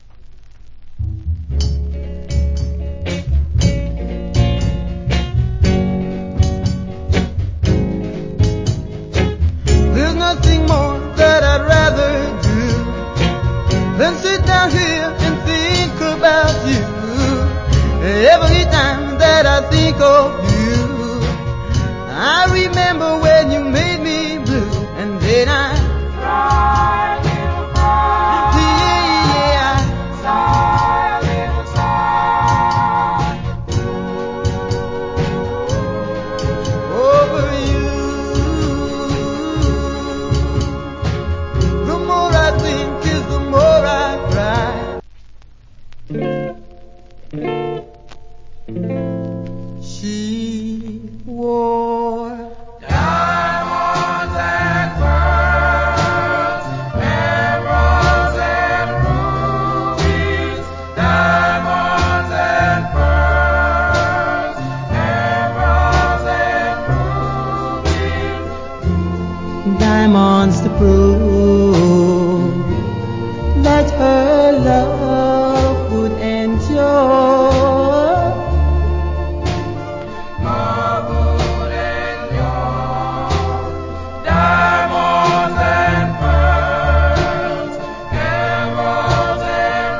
Cool Jamaican Soul.